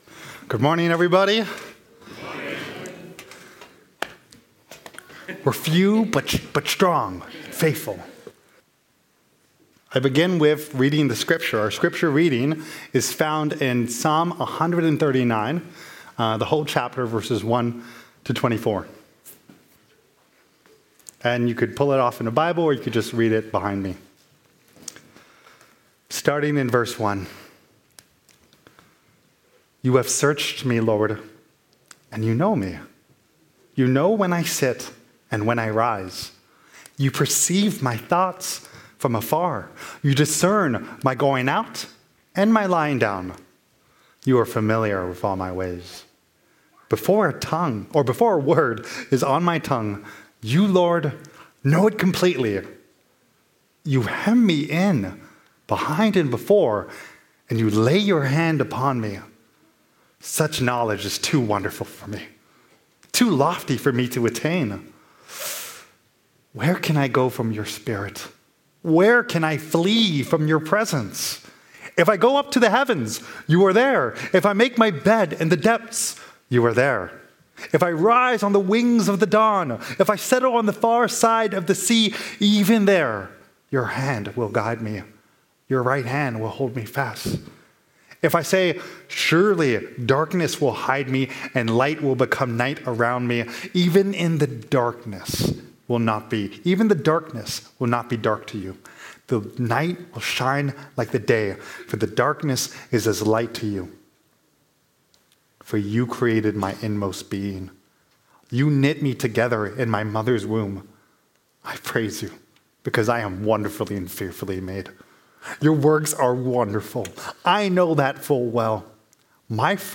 Series Sermons